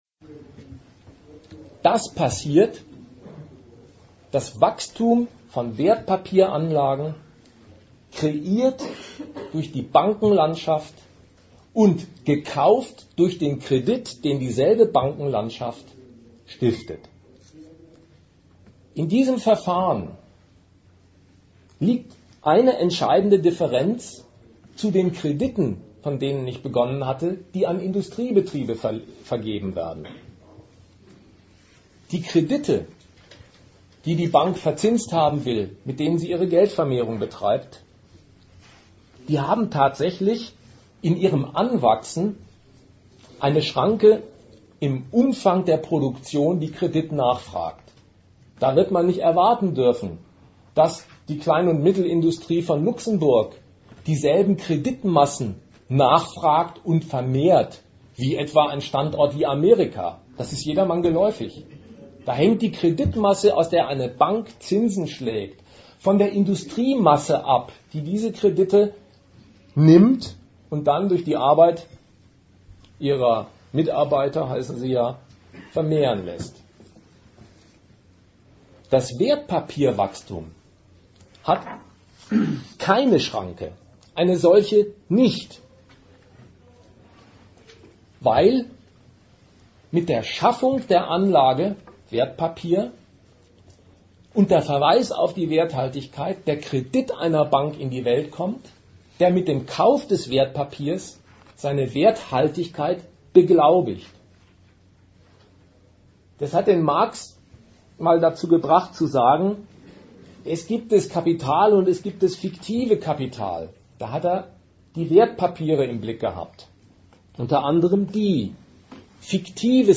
Veranstalter: Forum Kritik Regensburg
Datum 12.11.2008 Ort Regensburg Themenbereich Arbeit, Kapital und Sozialstaat Veranstalter Forum Kritik Dozent Gastreferenten der Zeitschrift GegenStandpunkt Der Herbst 2008 ist wie ein Crashkurs über die Frage, was es heißt, im Kapitalismus zu leben.